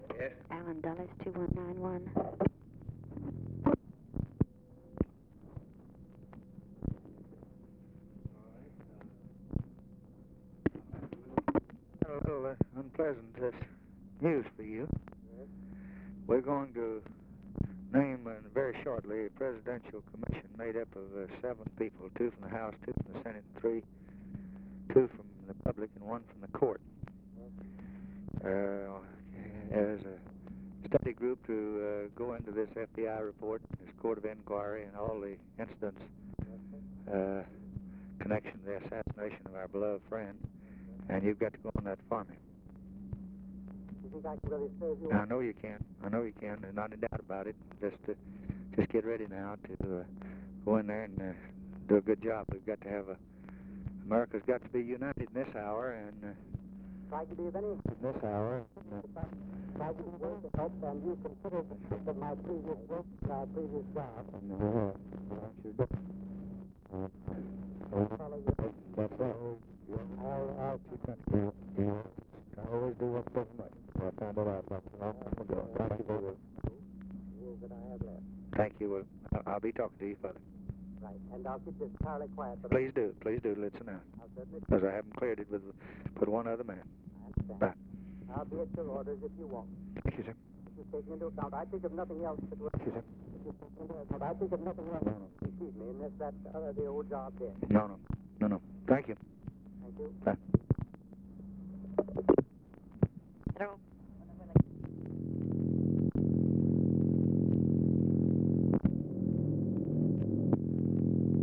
Conversation with ALLEN DULLES, November 29, 1963
Secret White House Tapes